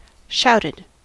Ääntäminen
Ääntäminen US Haettu sana löytyi näillä lähdekielillä: englanti Shouted on sanan shout partisiipin perfekti.